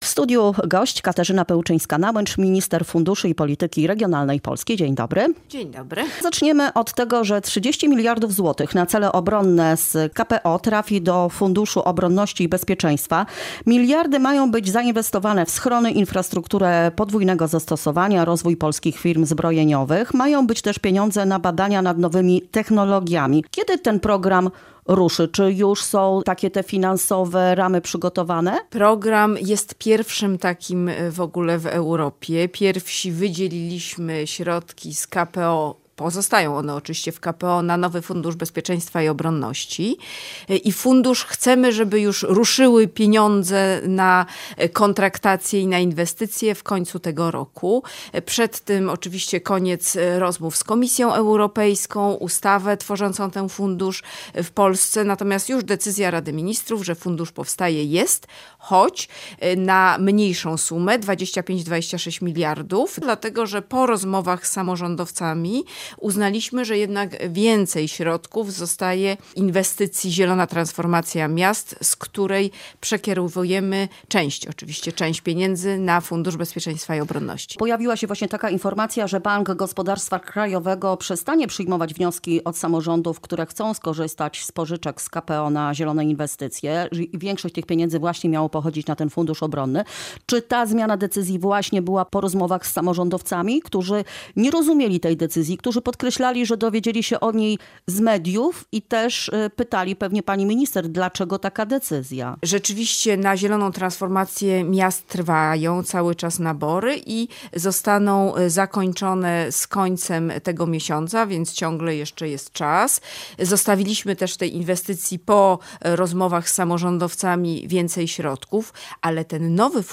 Jak podkreśliła w rozmowie z Polskim Radiem Rzeszów, spotkania z lokalnymi władzami mają kluczowe znaczenie przy projektowaniu polityki regionalnej.